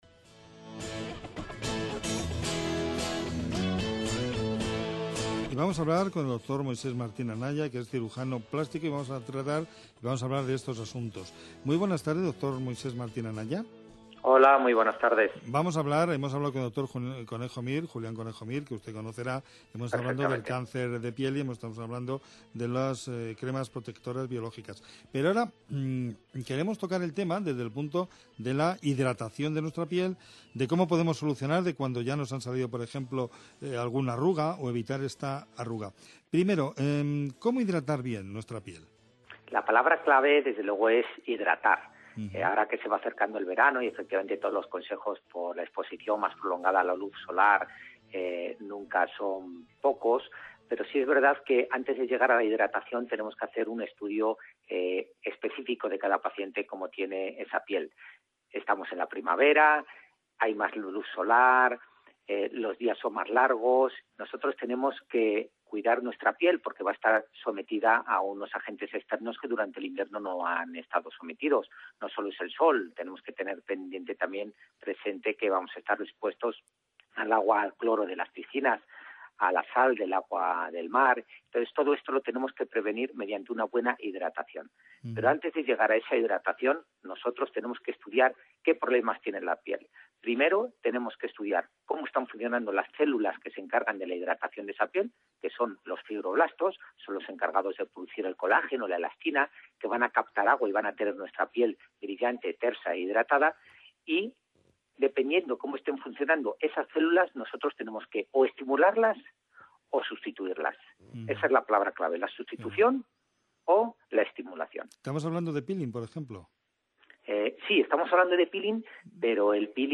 Hace poco me han realizado una entrevista telefónica desde Onda Madrid para hablar sobre la importancia de la hidratación a la hora de tratar y evitar las arrugas, entre otros asuntos.